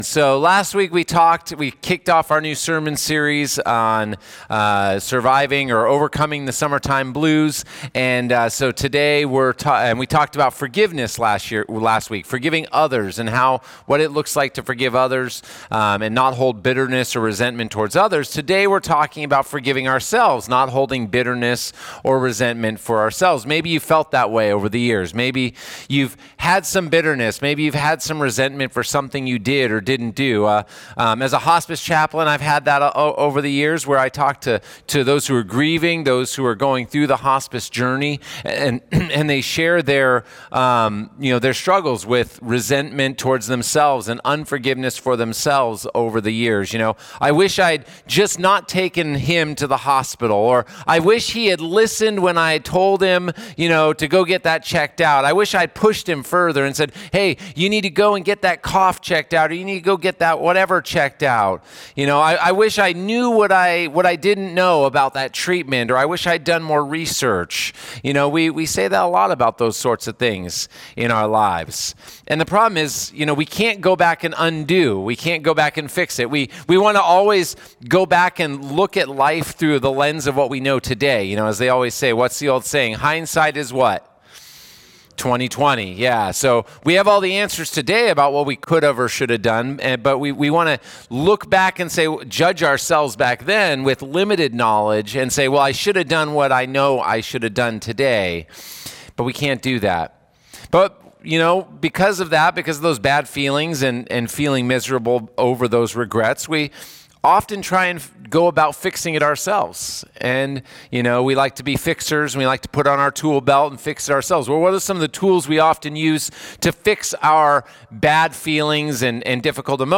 08-24-Sermon.mp3